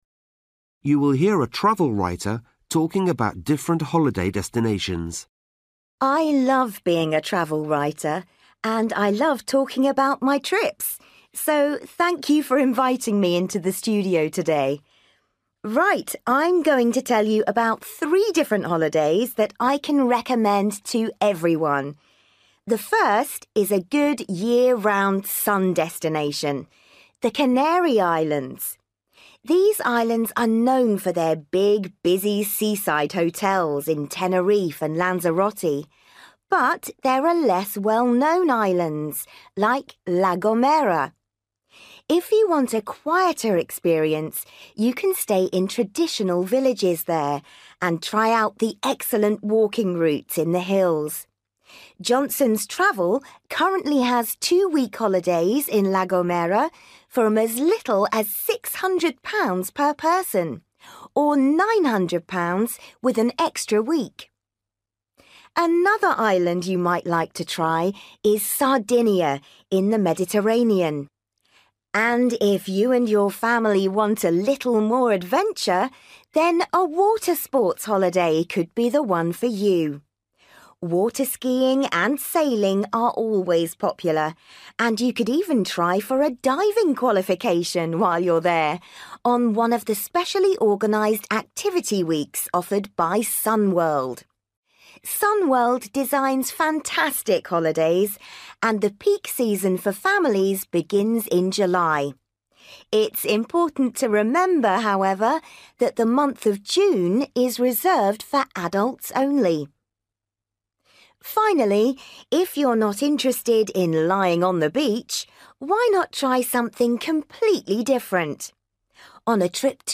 You will hear a travel writer talking about different holiday destinations.